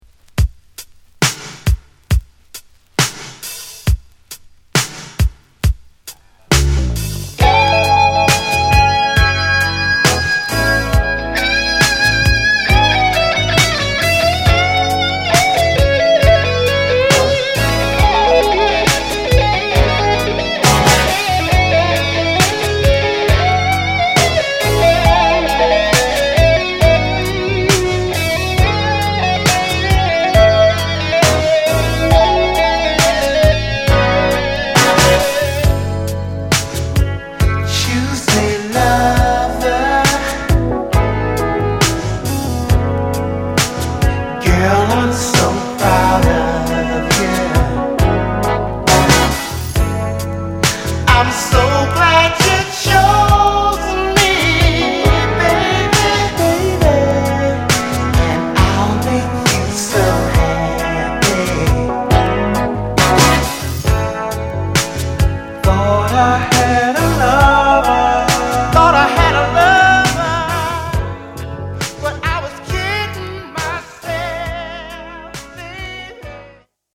は頭部分にドラムブレイク有り。